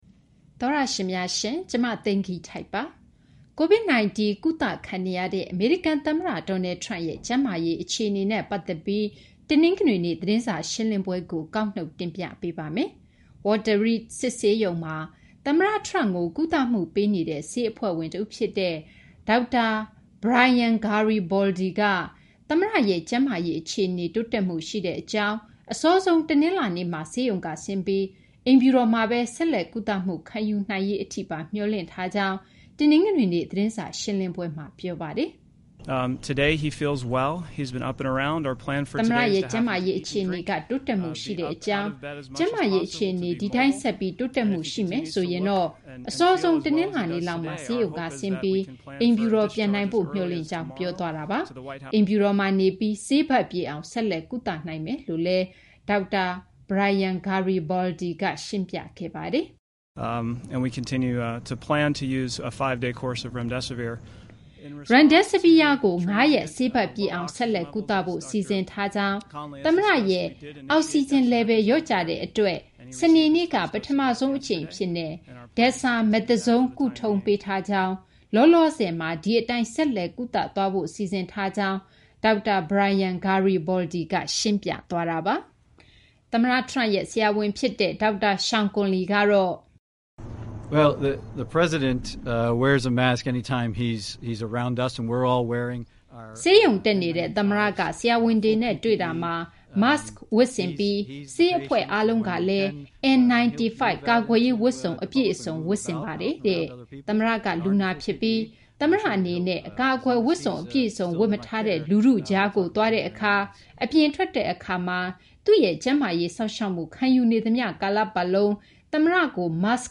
တနင်္ဂနွေနေ့ သတင်းစာရှင်းလင်းပွဲမှာ ပြောဆိုသွားပါတယ်။